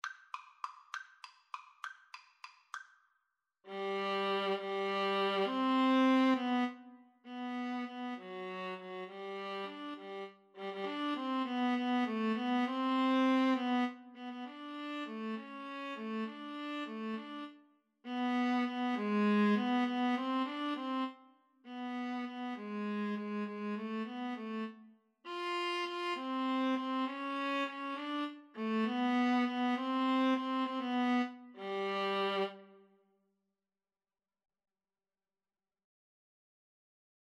3/8 (View more 3/8 Music)
Classical (View more Classical Viola Duet Music)